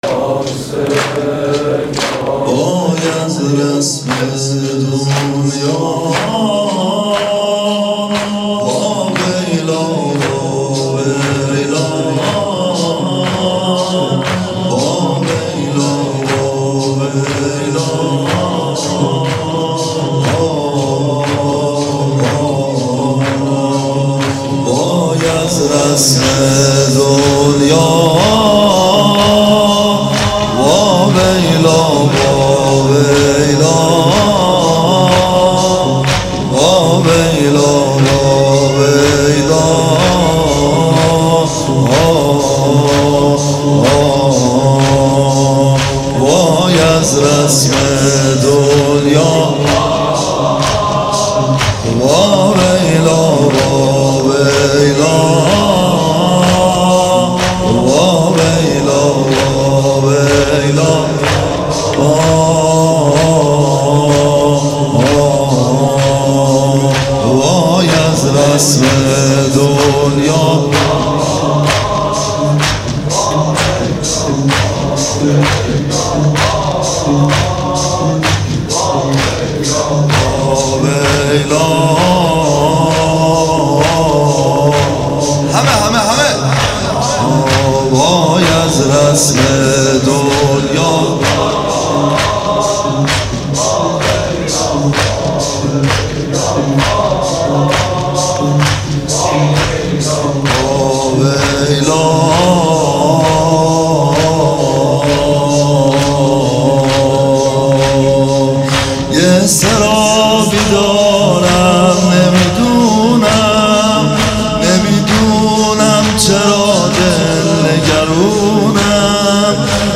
واحد | وای از رسم دنیا وای واویلا واویلا وای
مداحی
در شب دوم محرم 1439